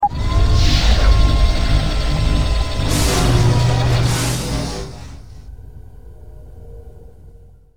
ships / salvaging / deploy.wav
deploy.wav